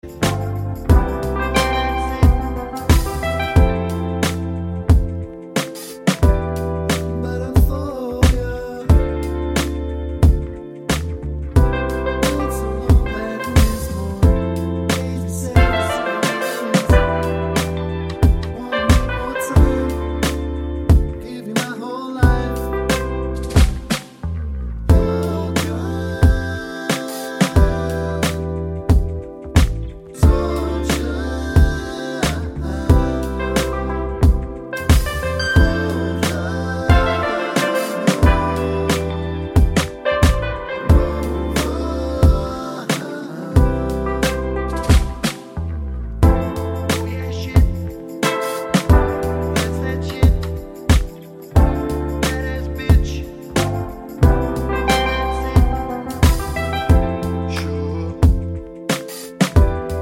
Explicit Backing Vocals Pop (2020s) 3:17 Buy £1.50